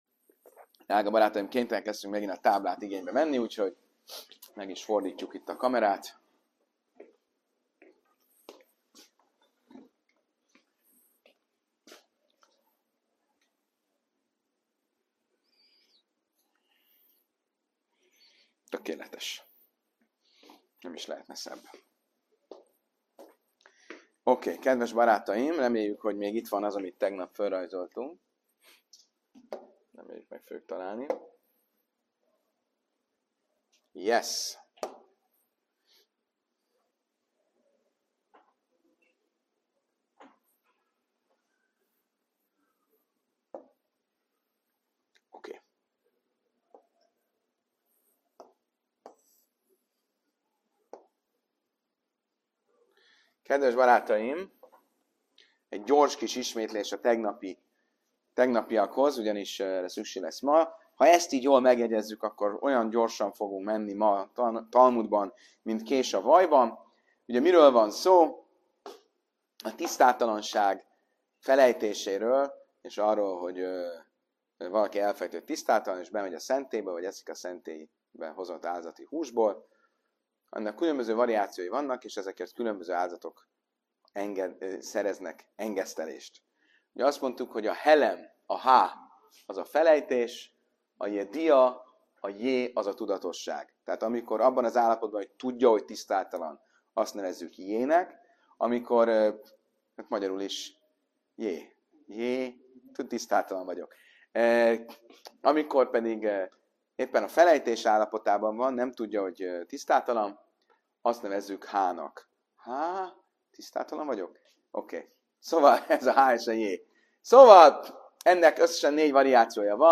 Mi történik, ha valaki soha nem is tudja meg, hogy vétkezett? A rabbi érthetően és humorral magyarázza el a helem és a jedia (felejtés és tudás) talmudi kategóriáit, miközben egy kecsketáblázat segítségével fejti ki a különböző áldozati típusok rendszerét – egészen odáig, hogy az előadás végén már a hallgatók is álmodnak a JHJ, HH és AHT rövidítésekkel.